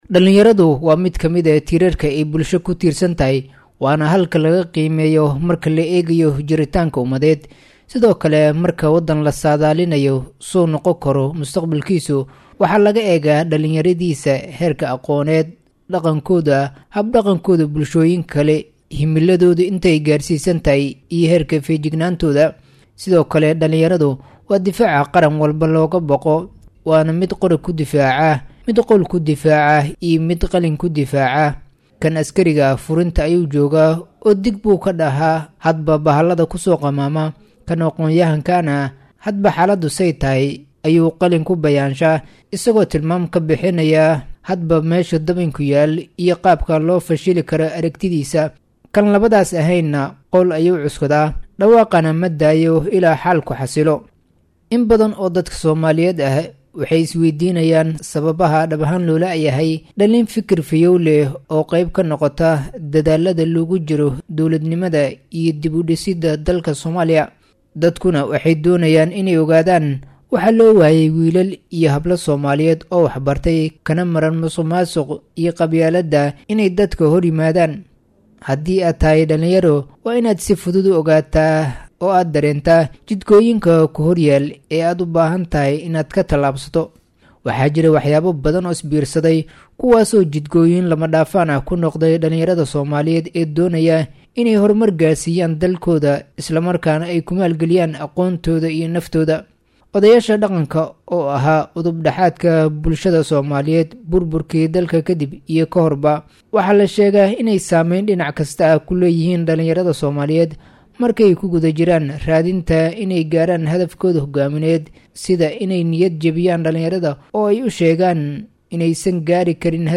Koox Dhallinyaro ah ayaa dooddan kaga qeyb qaadanaya sida dhallinyarada kaalin uga yeelay karaan horumarka dhaqaale ee dalka, sida lagu xallin karo in dhallinyarada aysan ka bixin dalka, cidda ay tahay in ka qeyb qaadato kabaajinta dhallinyarada Tahriibka iyo qataraha nololeed ee ay kala kulmaan.